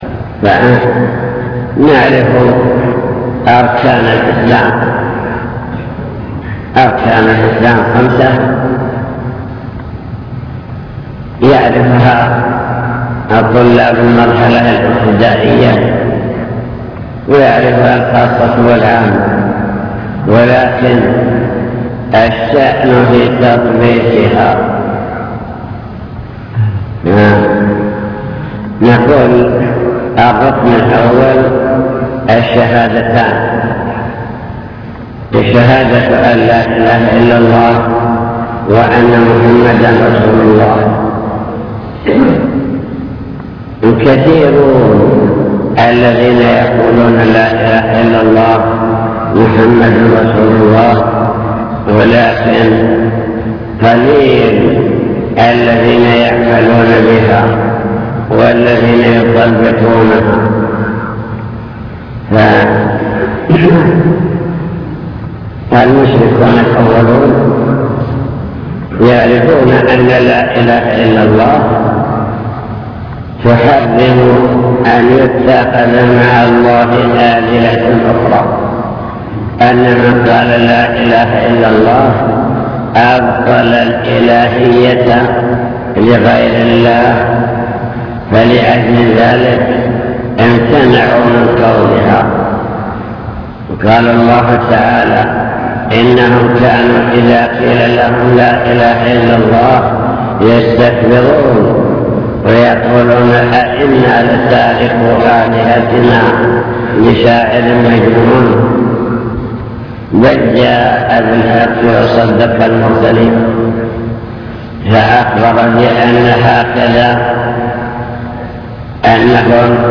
المكتبة الصوتية  تسجيلات - محاضرات ودروس  الإسلام والإيمان والإحسان